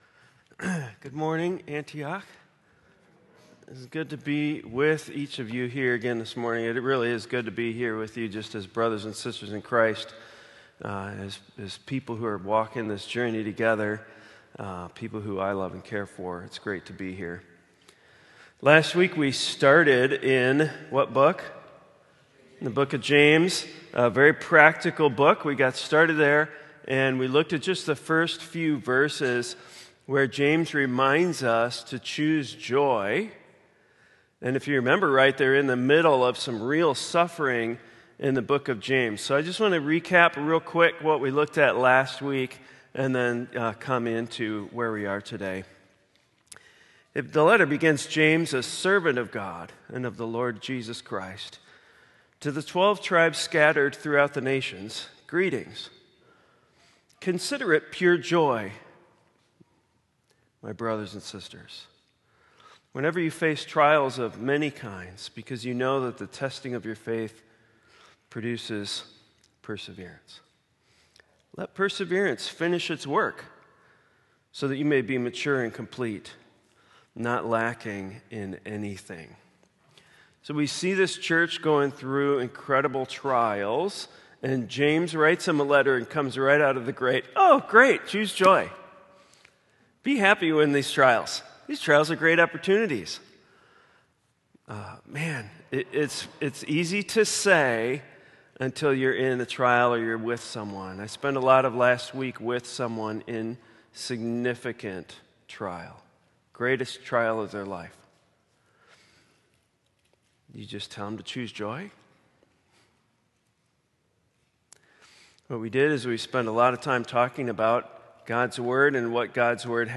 sermon-james-wisdom.m4a